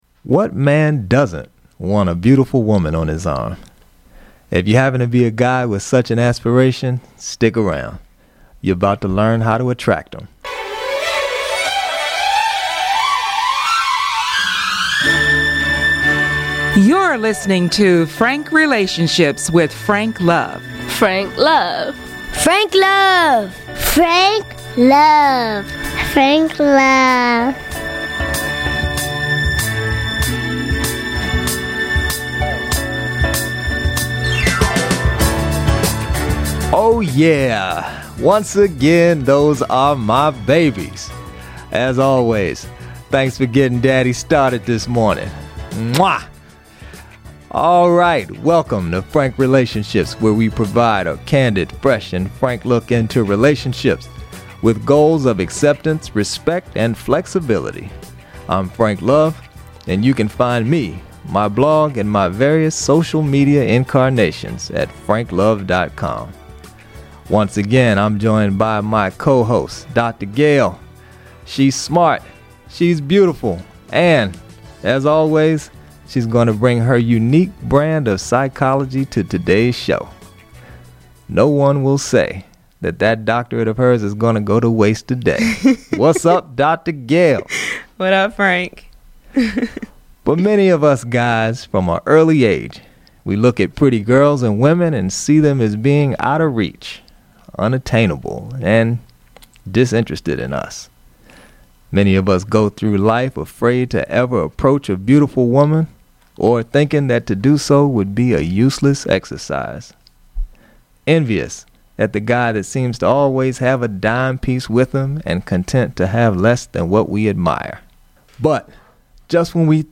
Podcast Episode: What man doesn’t want beautiful women on his arm? If you happen to be a guy with such an aspiration, stick around, you are about to learn how to attract them.